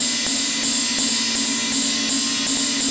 Ride 14.wav